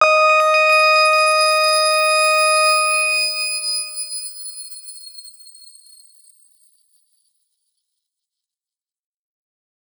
X_Grain-D#5-ff.wav